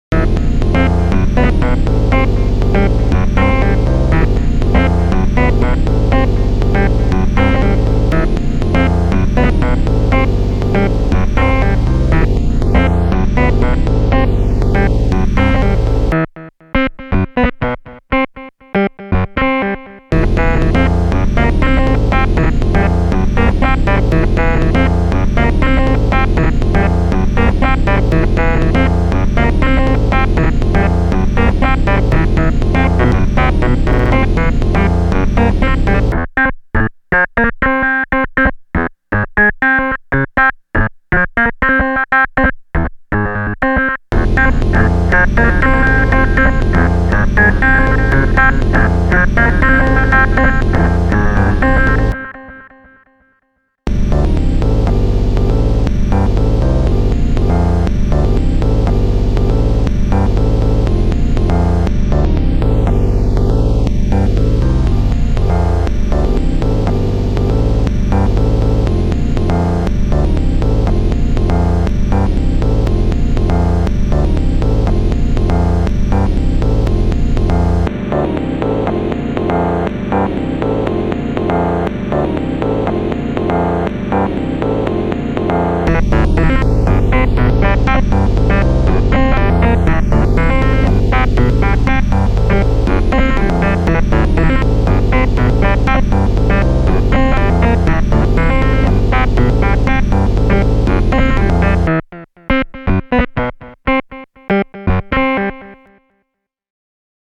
It's uber-noisy and dirty. Both the wavetable and sampler functions of the A-112 were used. The really dense static-y sound is the sampler playing the notes very slowly.
Thing I learned: how to reduce an LFO signal to swing between 1 and -1 volts to modulate the wavetable: this adds Hammond-like grit to the sound.